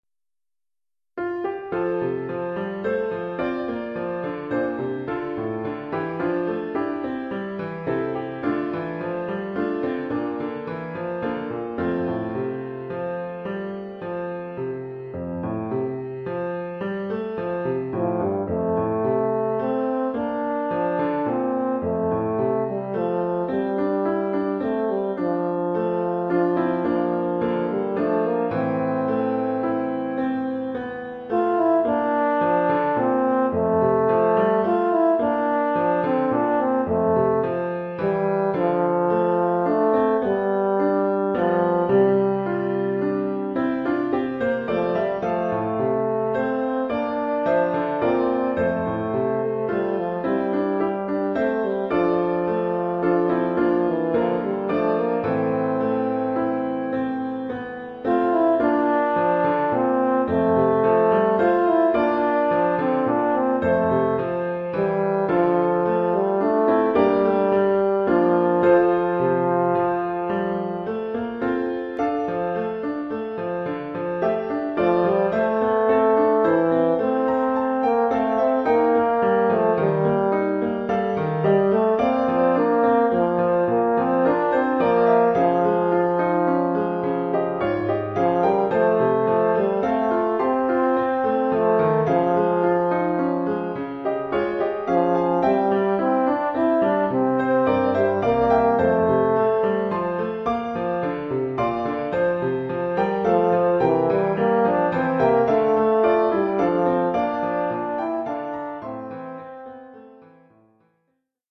Oeuvre pour saxhorn alto mib et piano.